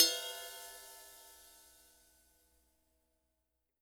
RIDE SIZZLE.wav